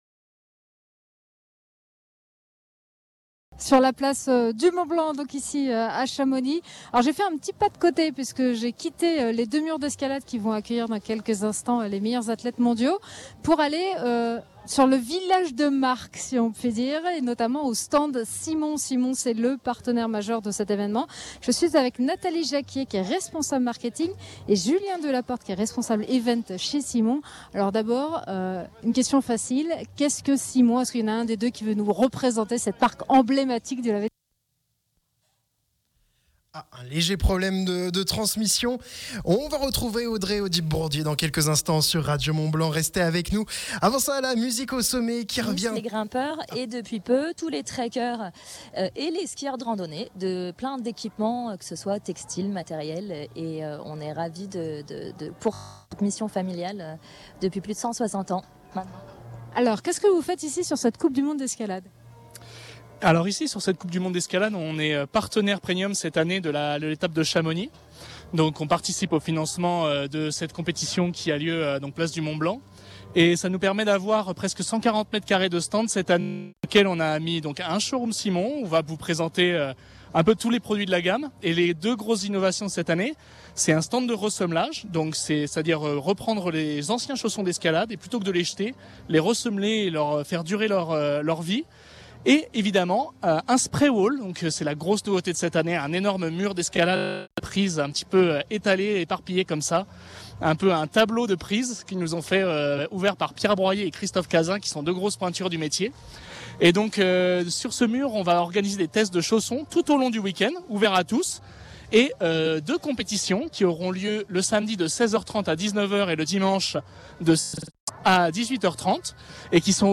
Radio Mont Blanc en direct de la Coupe du Monde d’Escalade à Chamonix !